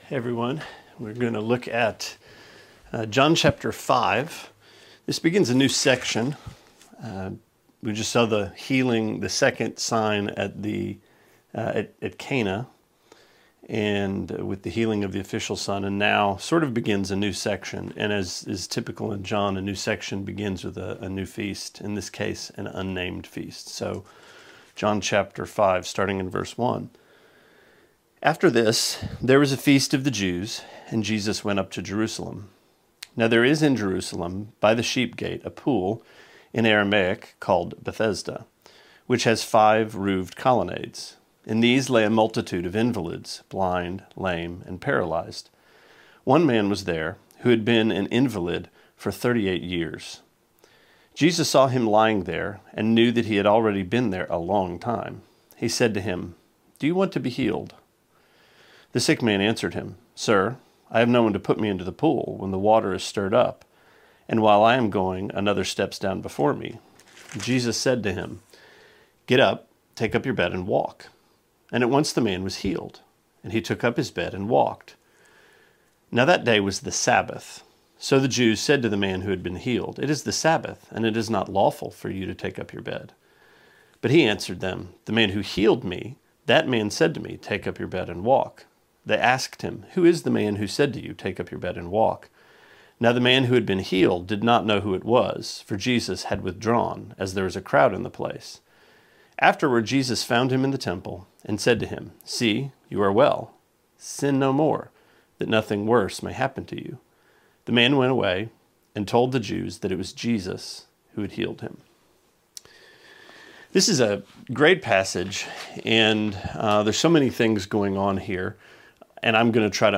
Sermonette 4/27: John 5:1-15: Thirty-Eight Years of Wandering